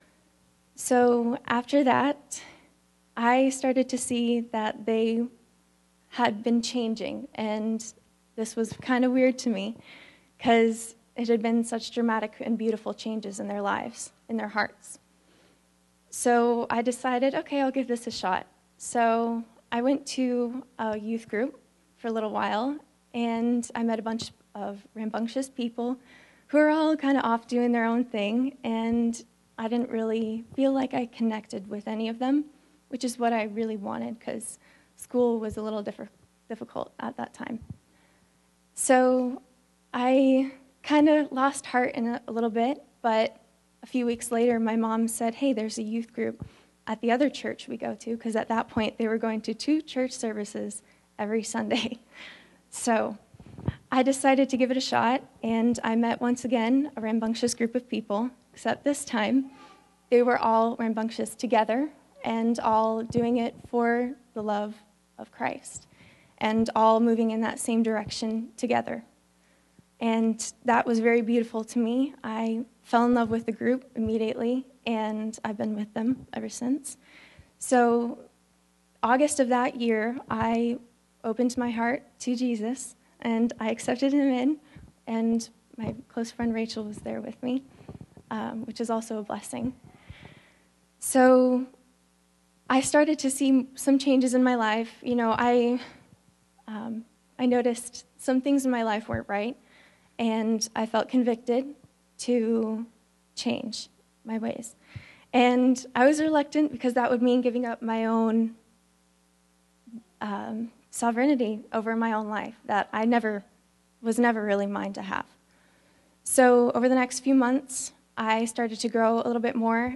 This recording includes a testimony (sorry we missed the very beginning), sermon and baptism.
Service Type: Sunday Service